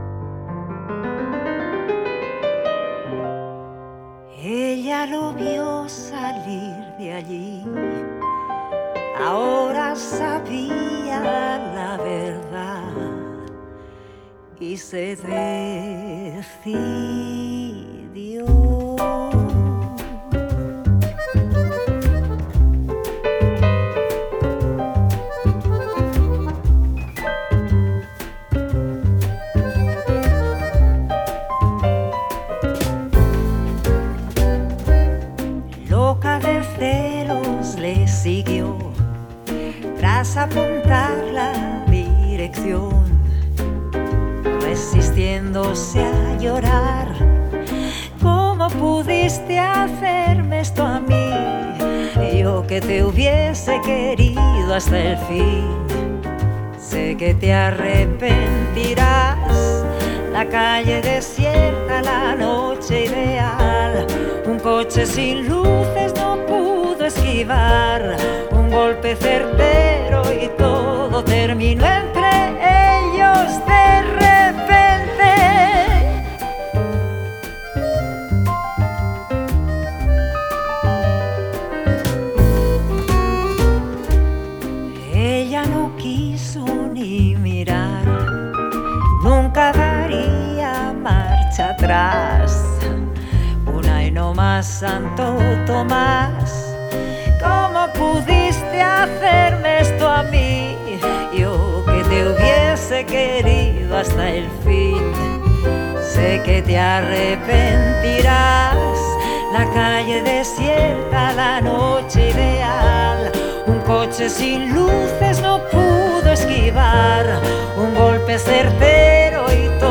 Spain • Genre: Pop